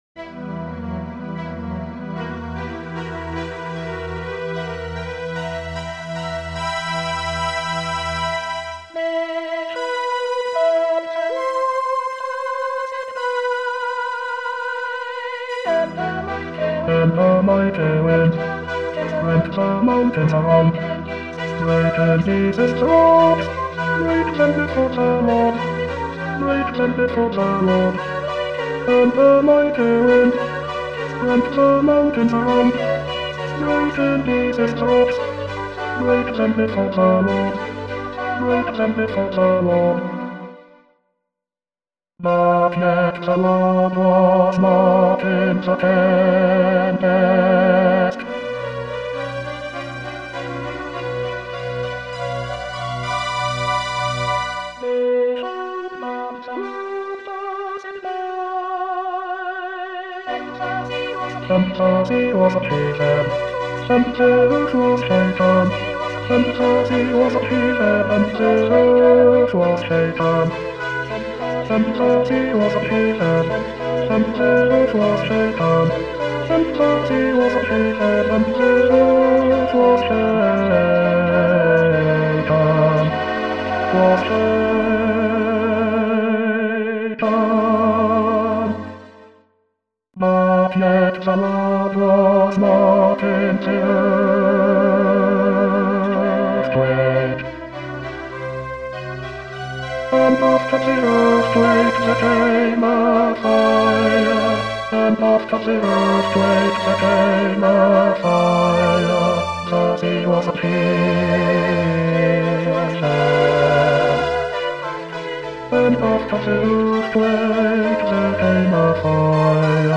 Bass